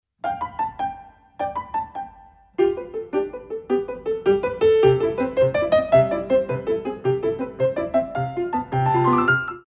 Ballet Class Music For Children aged 5+